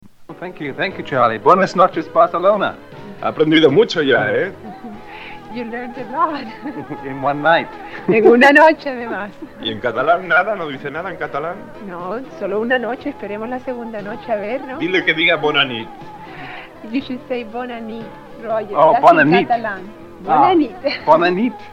Fragment breu de l'entrevista al músic Roger Hodgson, que havia format part del grup Supertramp (l'any 1984 Hodgson va iniciar la seva carrera en solitari).